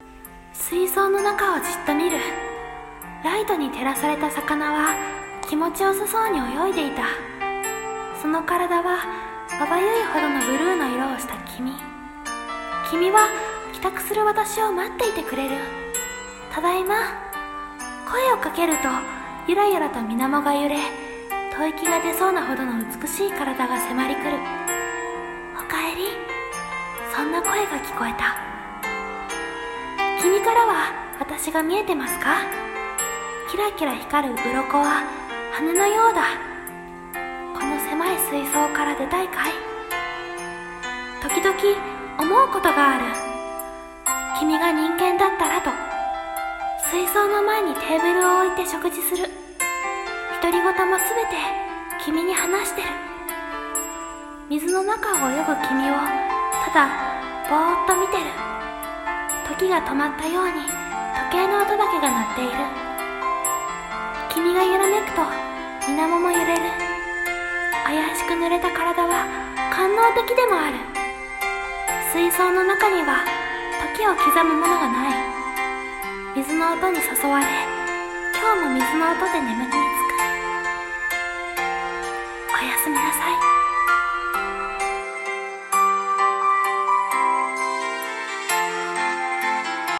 【声劇台本】熱帯魚~tropical fish！朗読